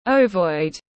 Hình trứng tiếng anh gọi là ovoid, phiên âm tiếng anh đọc là /ˈəʊ.vɔɪd/.
Ovoid /ˈəʊ.vɔɪd/